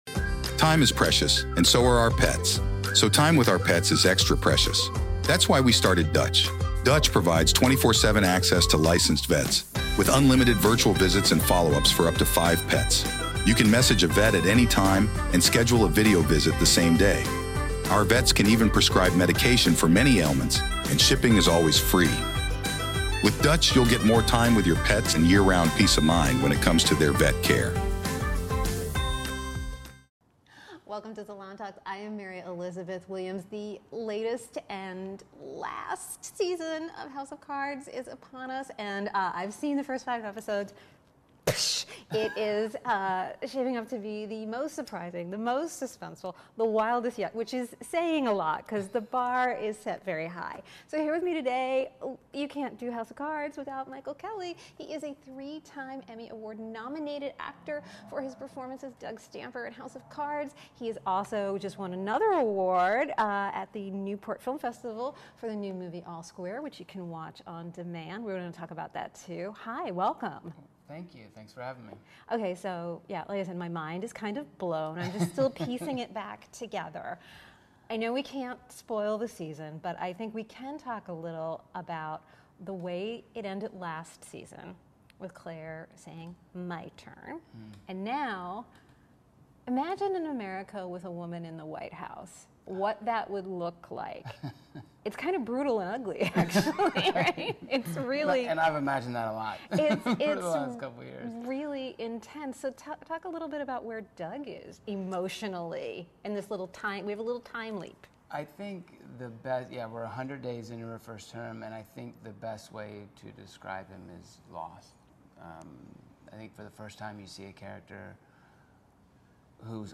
About “Salon Talks” Hosted by Salon journalists, “Salon Talks” episodes offer a fresh take on the long-form interview format, and a much-needed break from the partisan political talking heads that have come to dominate the genre.